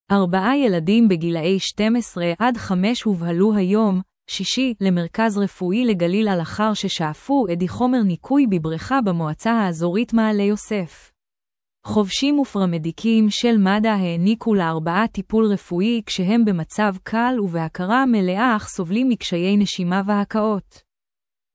ar-ba-ʿa yel-di-m ba-gi-la-yi 12-5 hu-va-ḥlu ha-yom (shi-shi) la-mer-kaz re-fu-i la-ga-lil al-ḥar sha-a-fu a-di ḥo-mer ni-kuy ba-bri-kha ba-mo-a-tsa ha-a-zo-rit ma-a-le yo-sef. ḥo-va-shim u-fe-ra-me-di-kim shel ma-da he-ʿni-ku la-ar-ba-ʿa ti-pul re-fu-i ka-she-hem ba-ma-tsav kal u-va-ḥke-ra me-le-a a-kha so-va-lim mi-kshi-y ne-shi-ma u-ha-ku-ot.